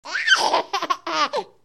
babylaugh2.ogg